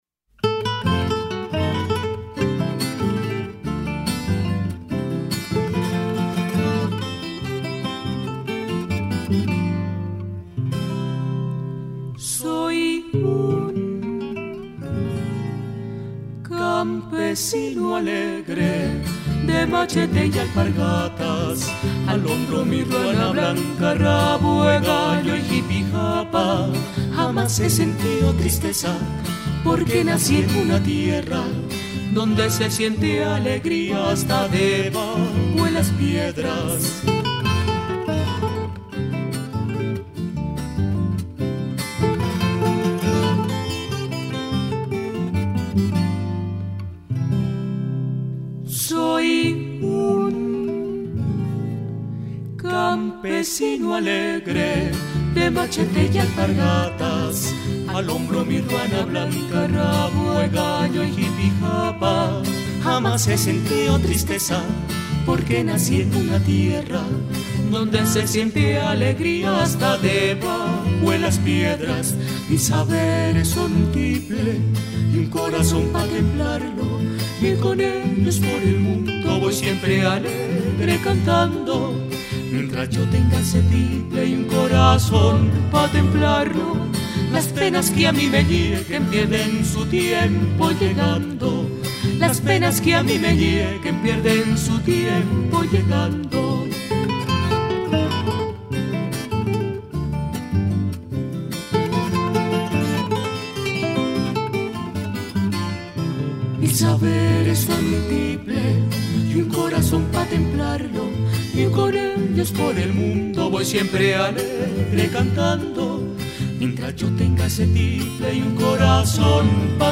Contralto y Guitarra en Si Bemol
Tenor y Tiple en So bemol
BAMBUCO